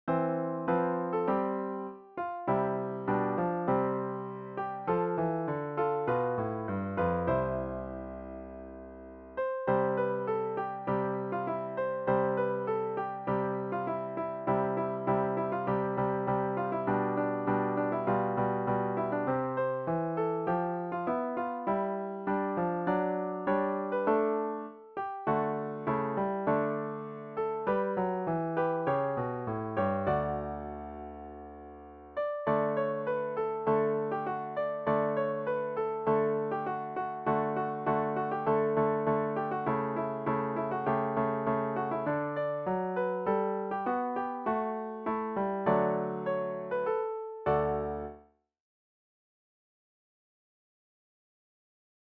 Produced digitally in Finale music notation software